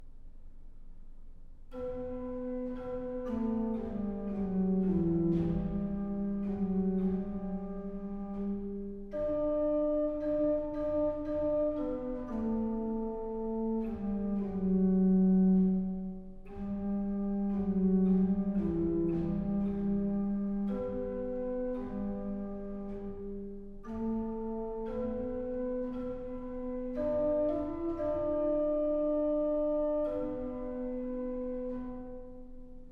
tenor
tenor-nous-chanterons-pour-toi.mp3